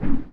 WHOOSH_Deep_Smooth_01_mono.wav